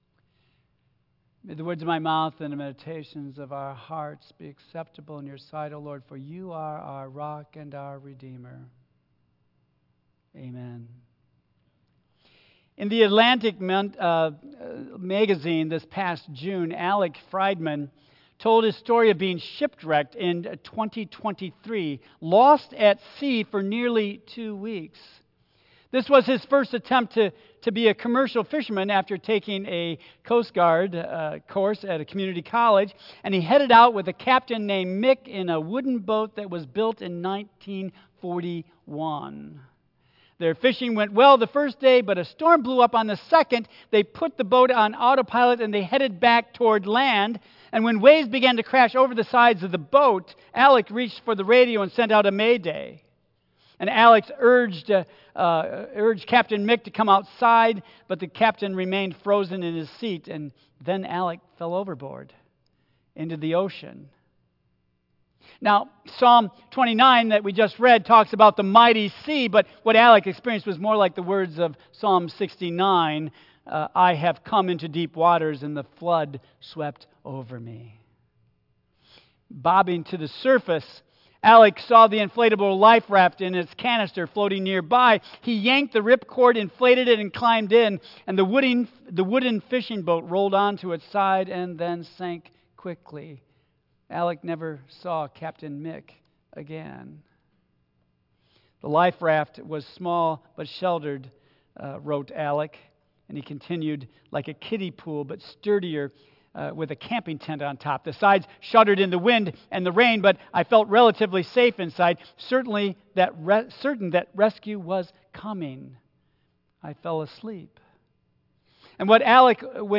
Tagged with baptism of Jesus , Michigan , Sermon , Waterford Central United Methodist Church , Worship Audio (MP3) 8 MB Previous Positive Perseverance Next The Attitude of Gratitude